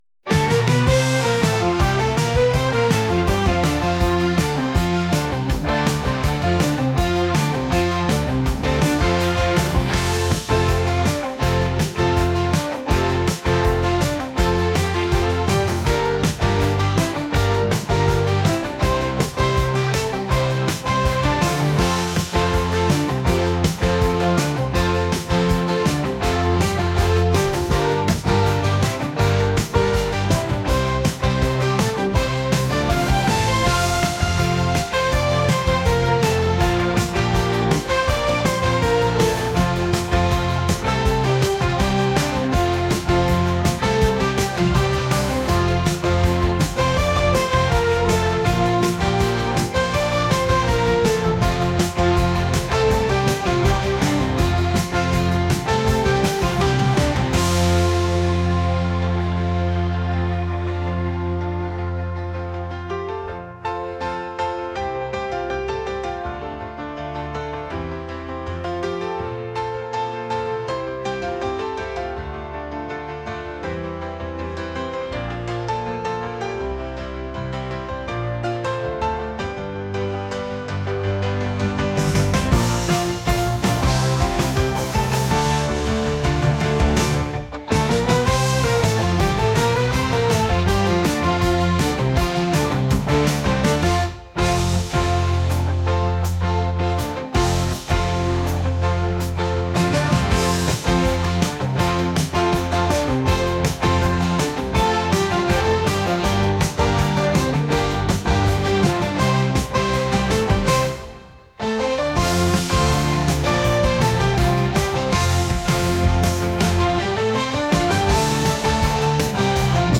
rock | retro